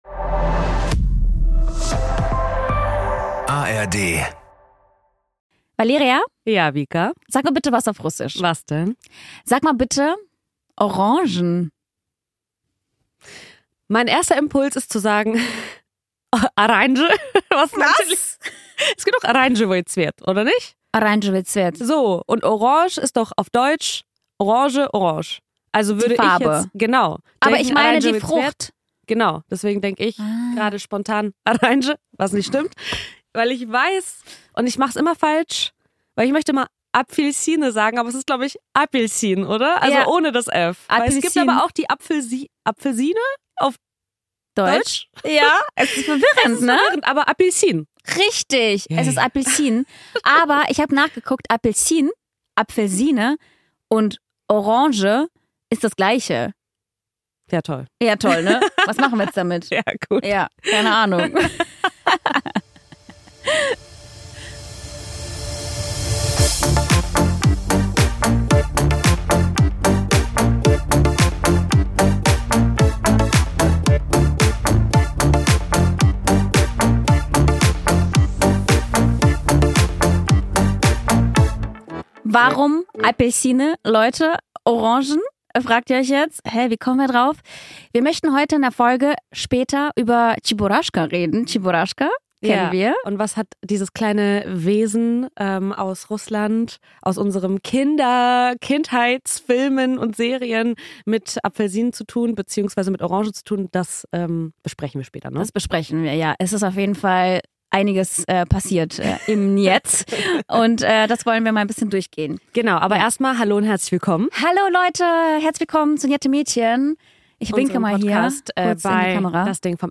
In dieser Folge führen die njetten Mädchen ein typisch russisches „Küchengespräch“ mit euch.